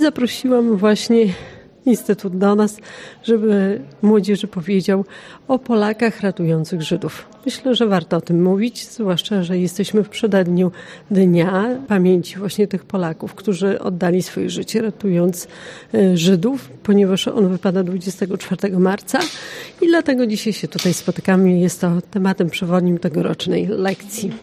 ’Zawołani po imieniu na Mazowszu’ to tytuł wydarzenia zorganizowanego w Starostwie Powiatowym w Łomży.
To jedna z wielu inicjatyw, dzięki której poznajemy przeszłość naszego regionu [DOWNLOAD file=”” name=””]- mówi Maria Dziekońska, wicestarosta łomżyński: